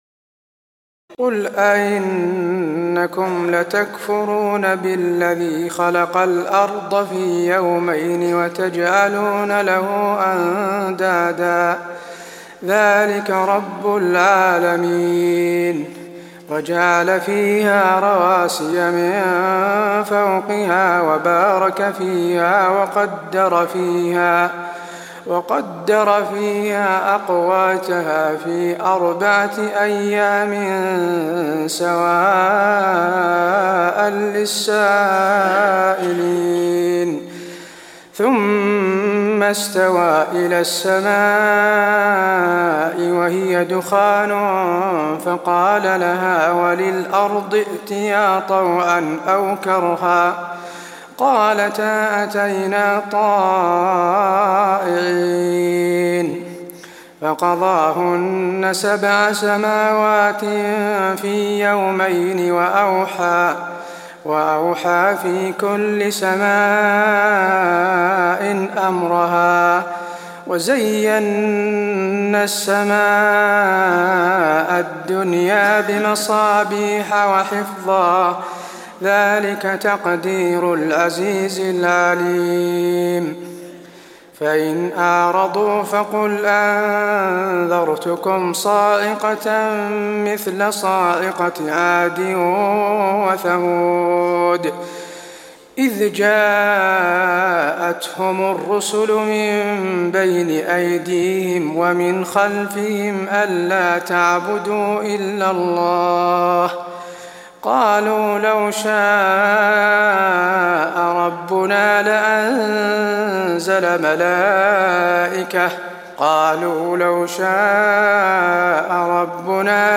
تراويح ليلة 23 رمضان 1423هـ من سورة فصلت (9-46) Taraweeh 23 st night Ramadan 1423H from Surah Fussilat > تراويح الحرم النبوي عام 1423 🕌 > التراويح - تلاوات الحرمين